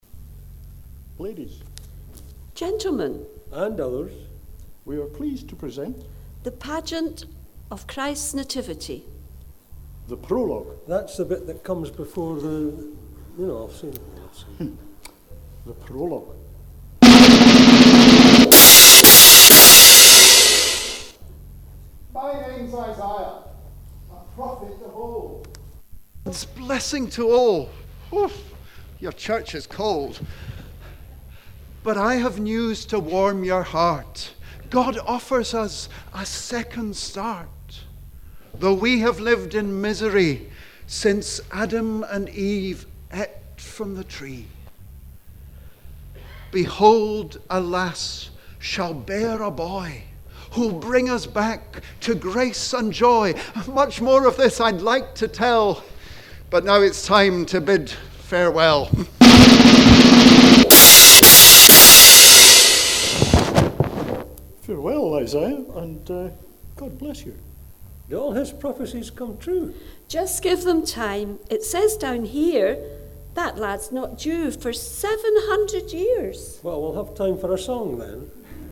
introducing the prophet Isaiah who storms on to the stage to a drum roll and clash of cymbals. Isaiah tells us of Gods blessing to mankind, a second start.